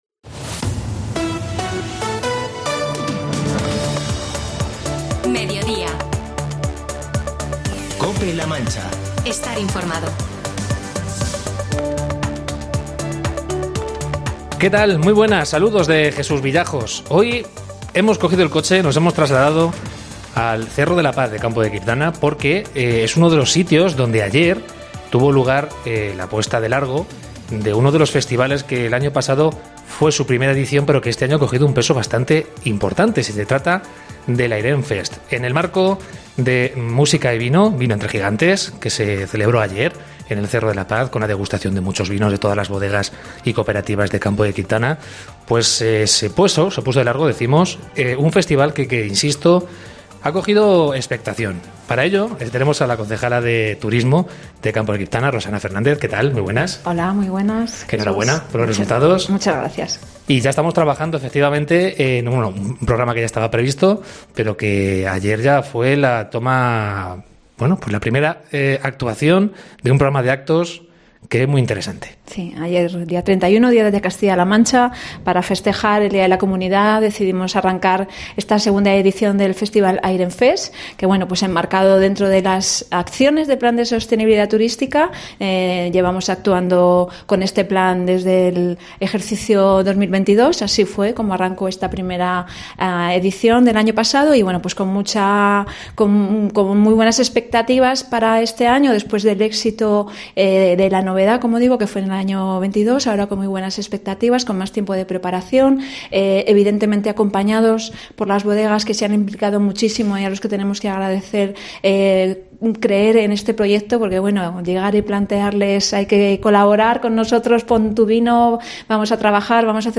Entrevista a Rosana Fernández, concejal de turismo del ayuntamiento de Campo de Criptana